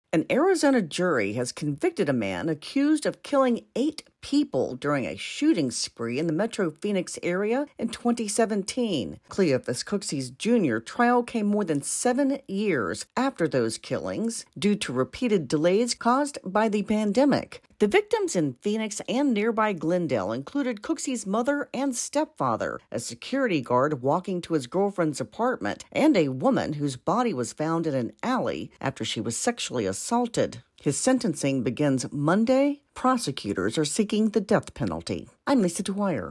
reports on the convictions of an Arizona serial killer.